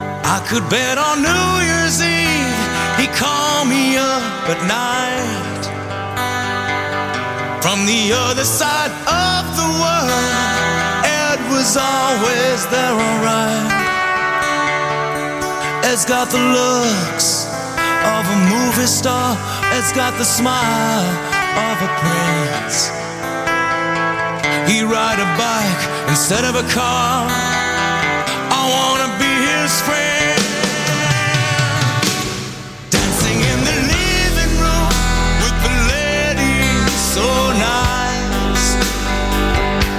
progressive rock , баллады